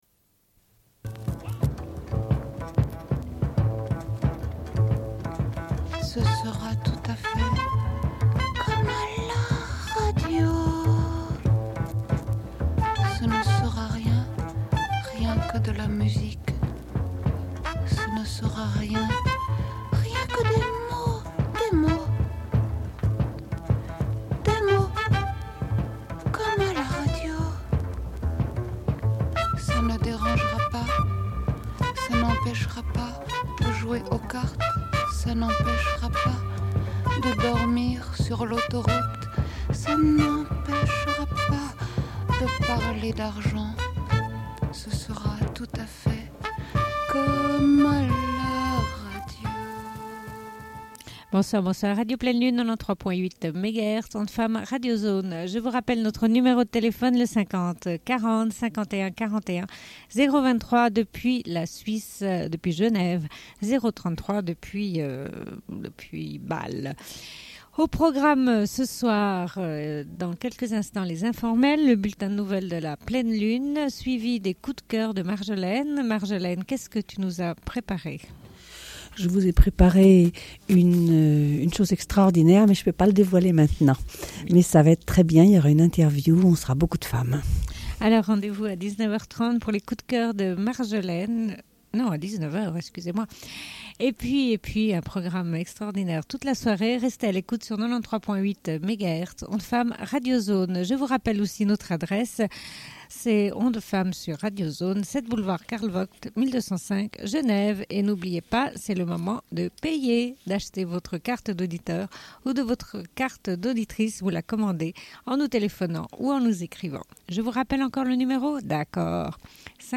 Une cassette audio, face B30:25